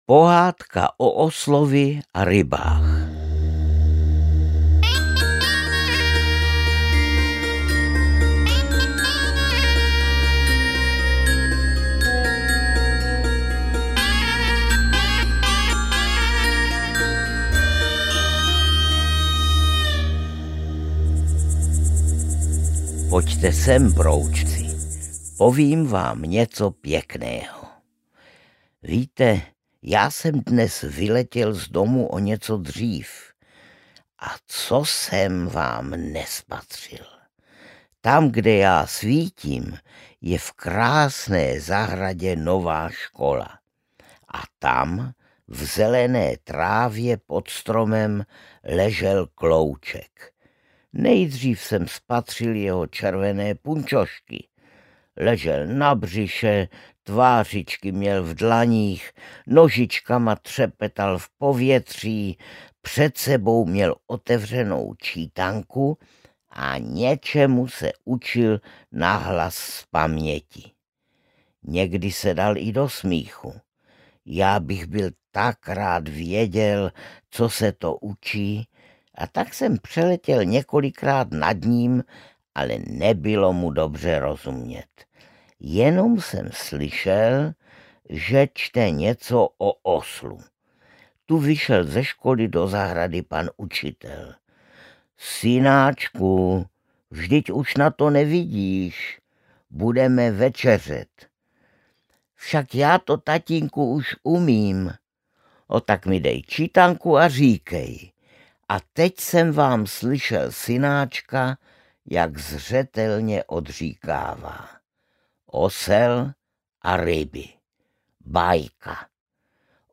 Broučci: Broučkovy pohádky audiokniha
Ukázka z knihy